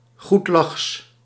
Ääntäminen
IPA : /ˈdʒɔɪfəl/